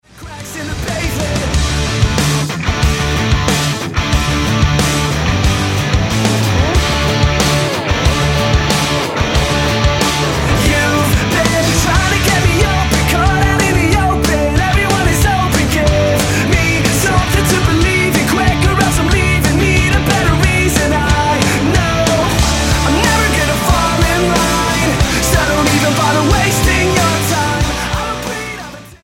Canadian punk band
Style: Rock